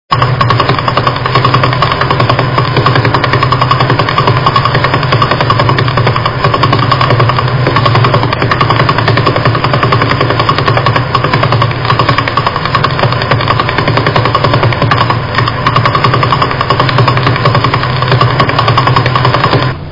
Las señales captadas, no serían audibles, si no se las modulara a su llegada en el receptor.
Los que siguen son 3 Pulsares tomados con la antena de 100 m. de Green Back.
Período: 0.089 seg.
Telescopio: NRAO 92m